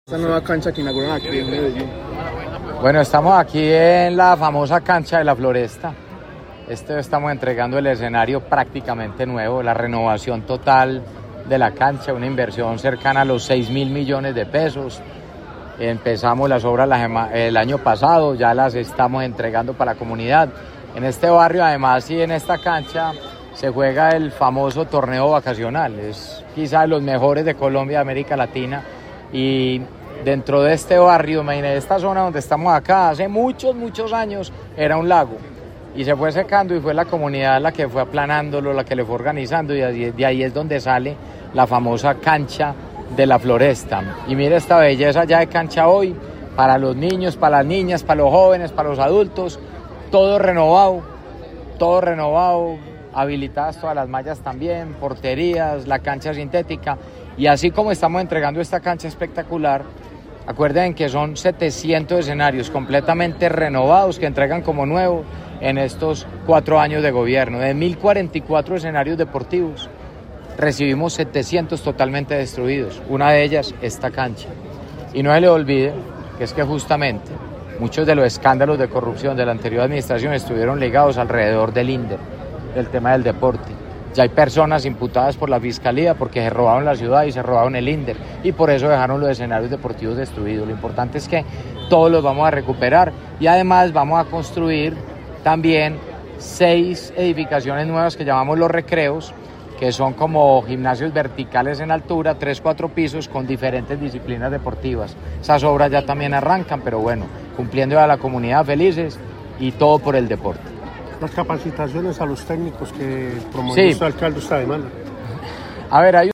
Alcalde-Renovacion-Cancha-Floresta-01.mp3